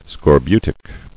[scor·bu·tic]